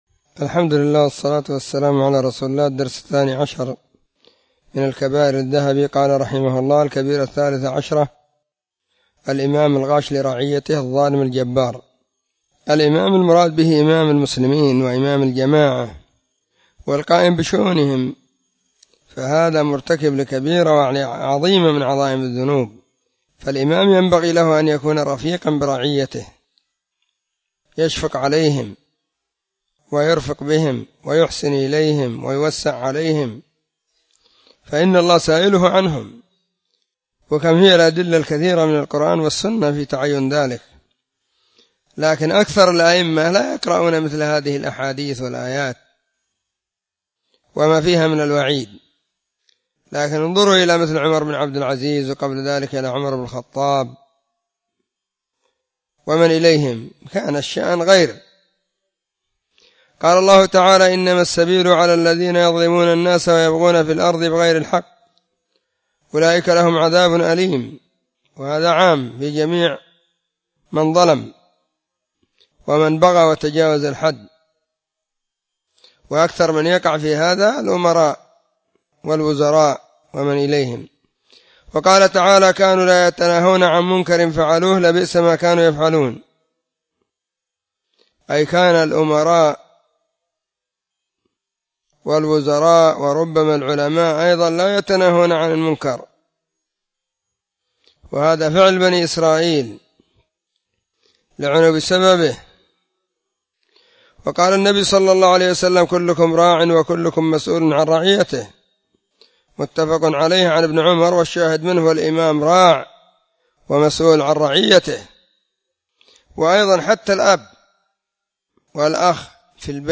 📢 مسجد الصحابة – بالغيضة – المهرة، اليمن حرسها الله.…
🕐 [بين مغرب وعشاء – الدرس الثاني]